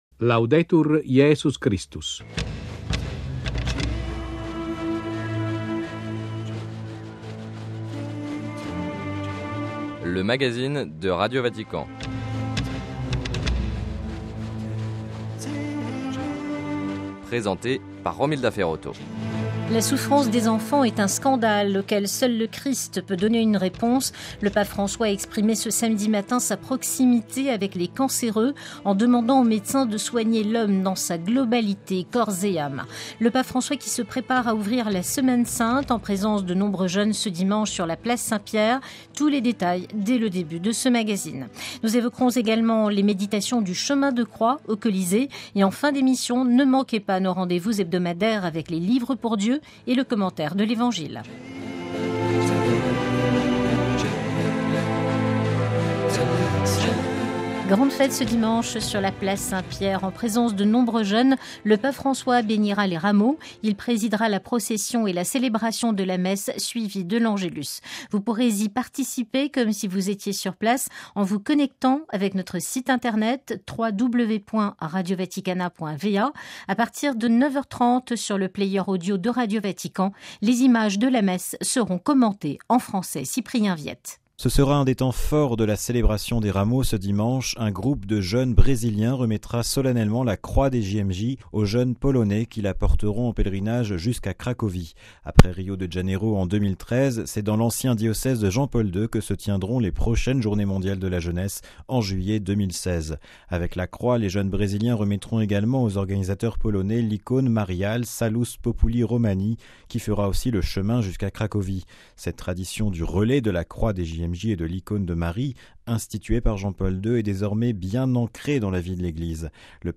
- Entretien avec le nonce apostolique à Caracas, Mgr Aldo Giordano, sur l'ouverture d'un dialogue entre gouvernement et opposition.